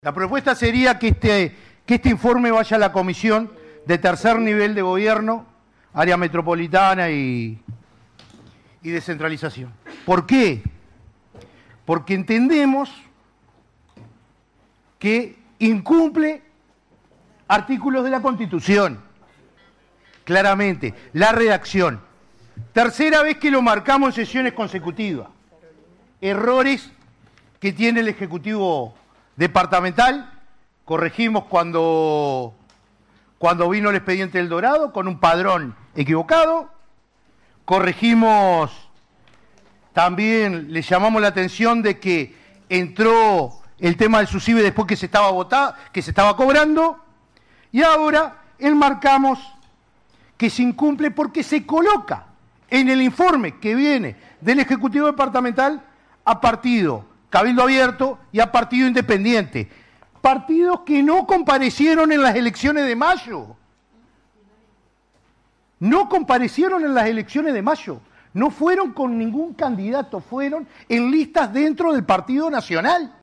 Palabras del Edil Germán González, Frente Amplio